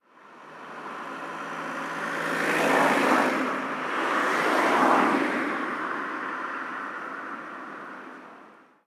Coche pasando rápido 2
coche
Sonidos: Transportes